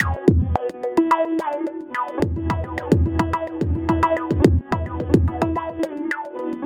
IND. SITAR-L.wav